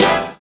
HIT XBAND 01.wav